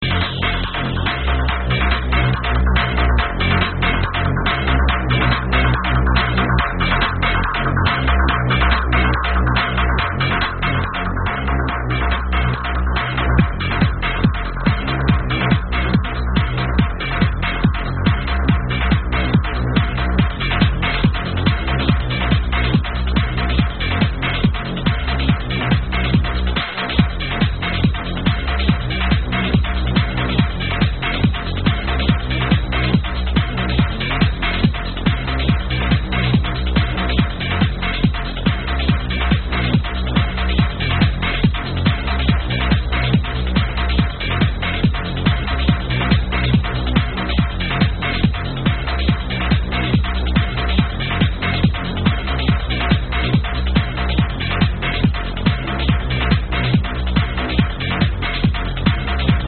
Techy goodness.